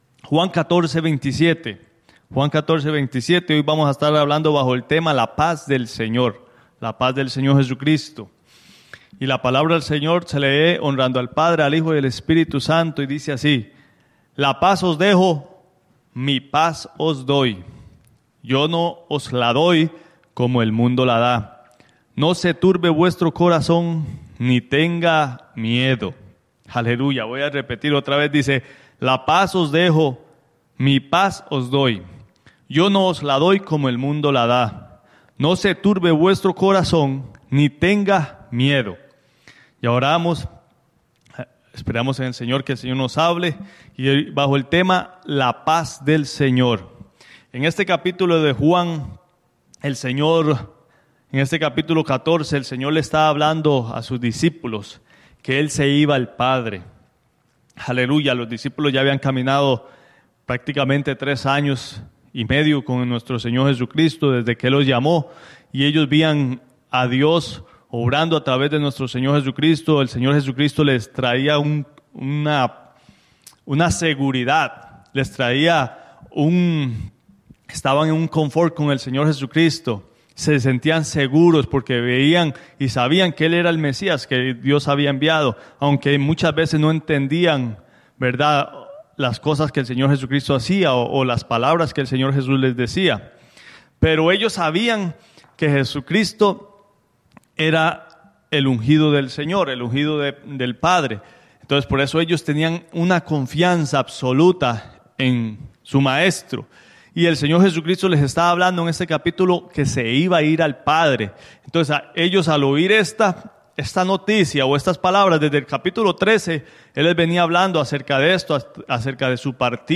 Iglesia Misión Evangélica
La Paz Del Señor | Predica